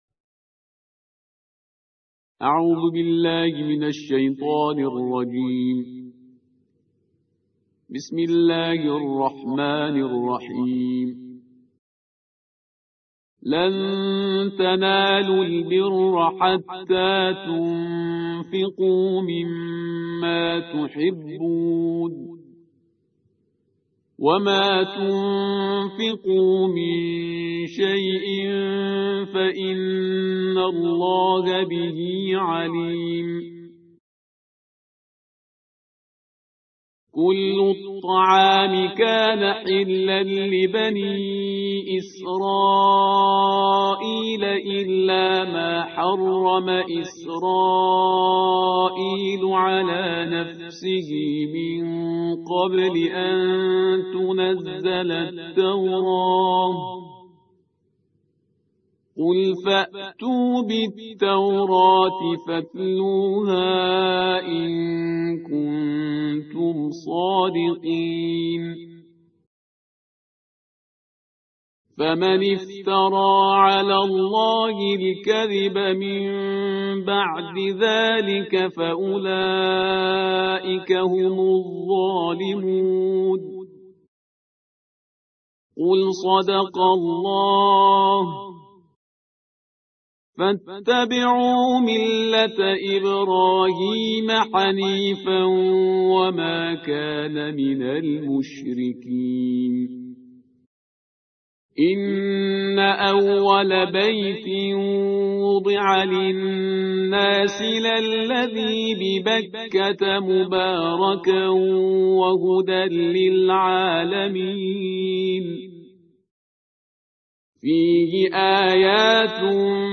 ترتیل جزء چهارم قرآن کریم